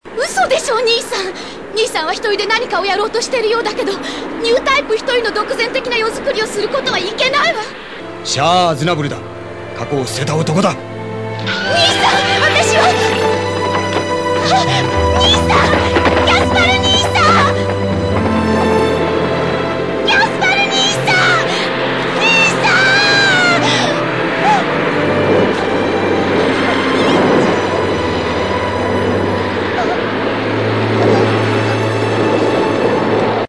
Audio file in Japanese. Sayla Mass, performed by You Inoue